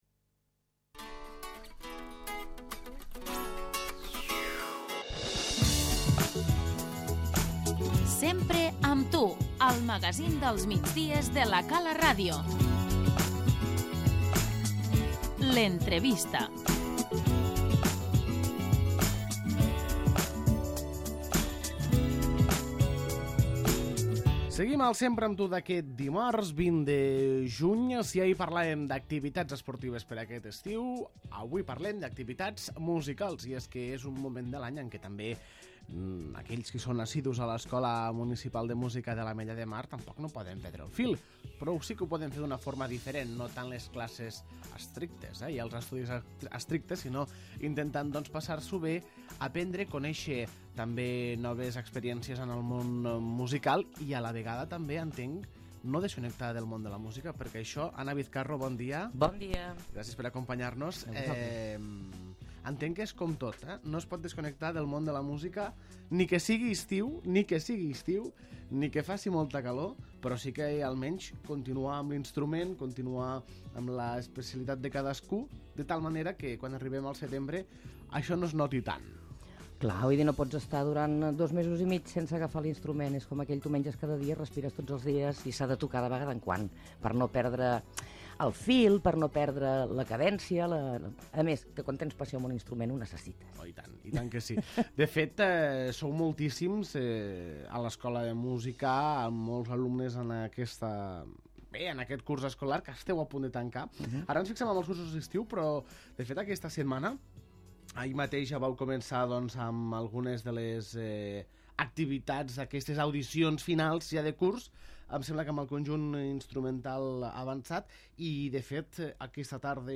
L'entrevista